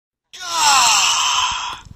Play, download and share Gaaaaaaahhhh original sound button!!!!
gaaaaahhh-online-audio-converter.mp3